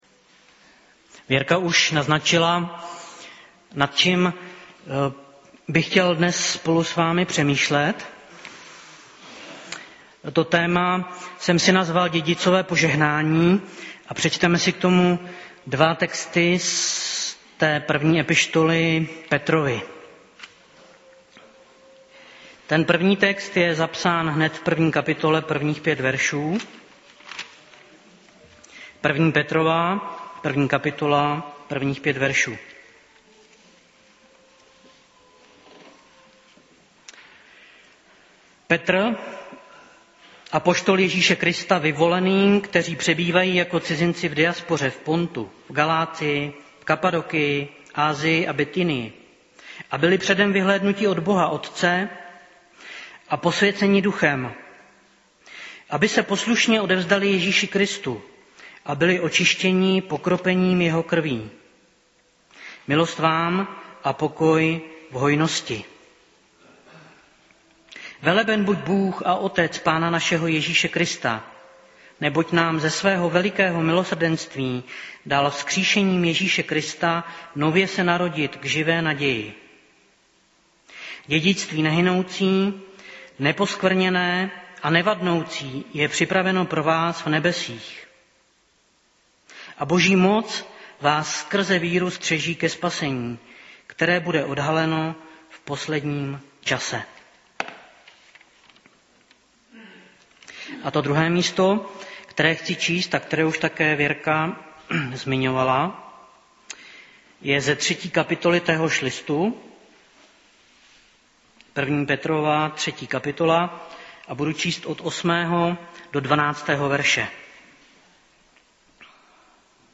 Kategorie: Nedělní bohoslužby Husinec